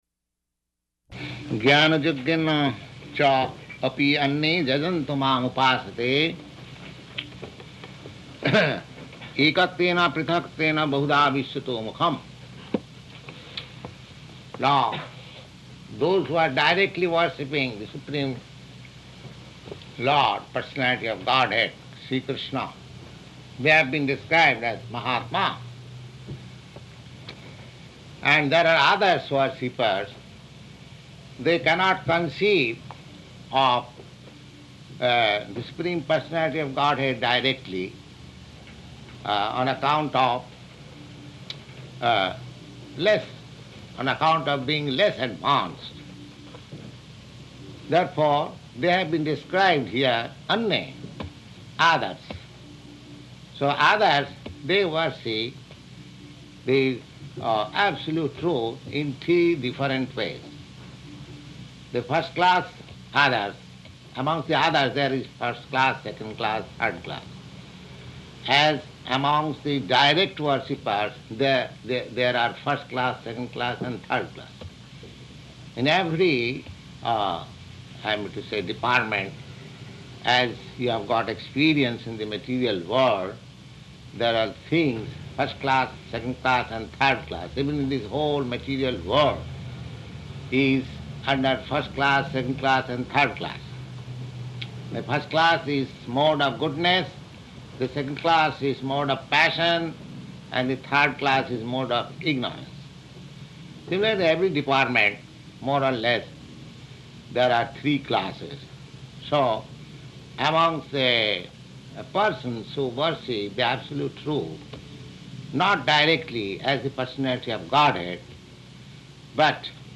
Type: Bhagavad-gita
Location: New York